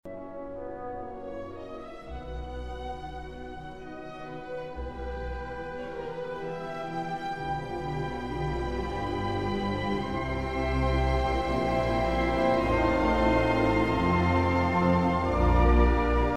ブライトコプフ新版だけが採用している楽譜による演奏と、同じ部分のベーレンライター版による演奏の音源も用意が出来ました。
オーボエのピッチとか、そういう次元の話ではありませんから。